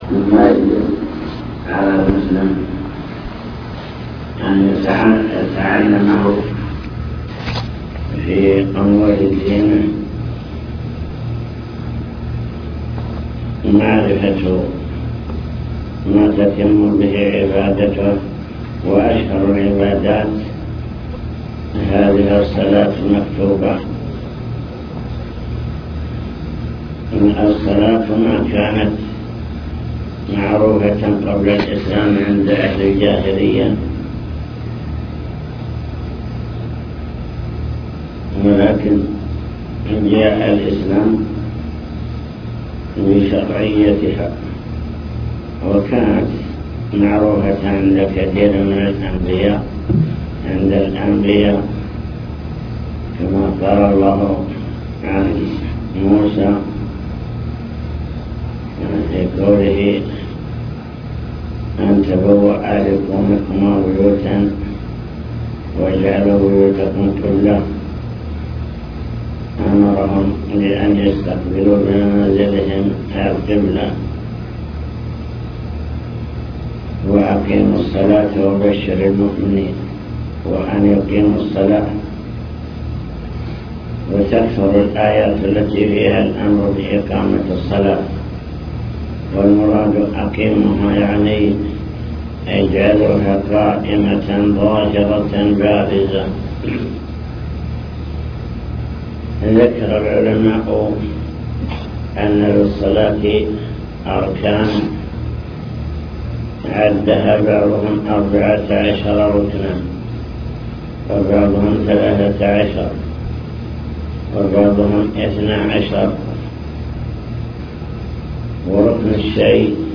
المكتبة الصوتية  تسجيلات - لقاءات  حول أركان الصلاة (لقاء مفتوح)